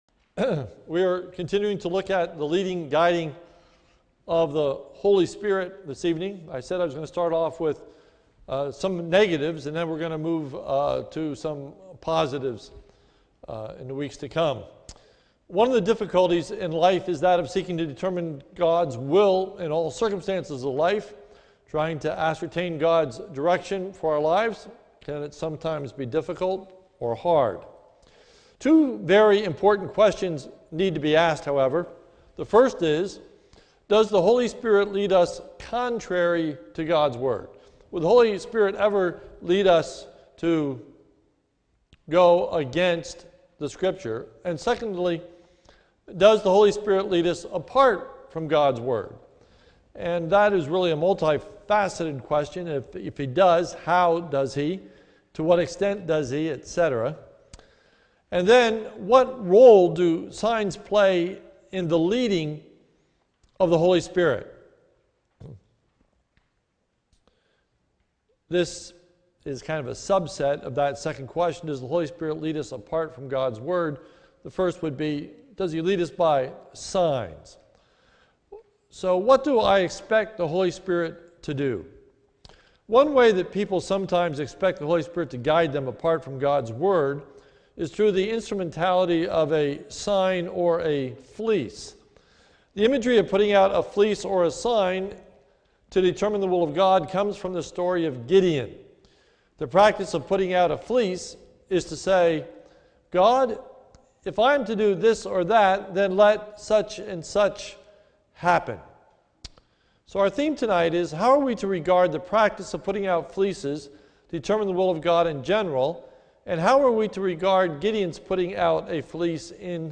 This is a sermon recorded at the Lebanon Bible Fellowship Church, in Lebanon, PA, on 10/19/2014 during the evening service